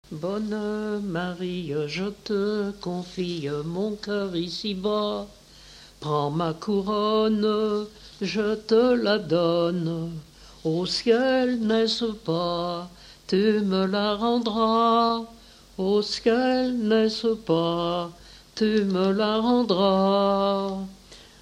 Mémoires et Patrimoines vivants - RaddO est une base de données d'archives iconographiques et sonores.
chanté à la communion solennelle
Pièce musicale inédite